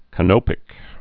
(kə-nōpĭk, -nŏpĭk)